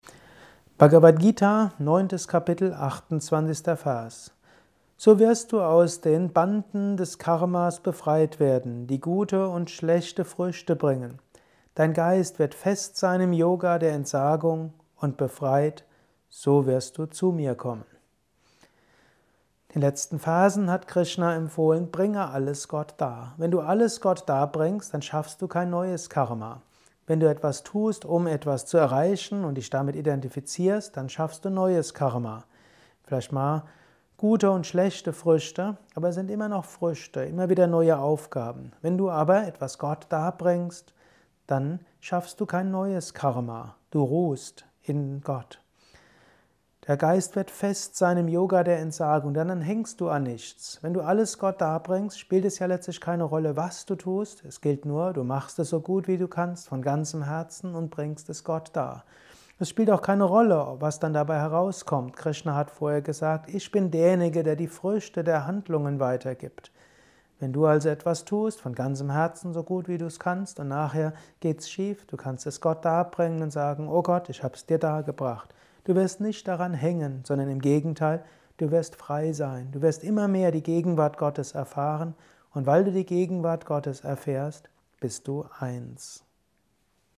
Höre einen kurzen Beitrag zur Bhagavad Gita Kapitel IX. Vers 28: Identifikation schafft Karma. Dies ist ein kurzer Kommentar als Inspiration für den heutigen Tag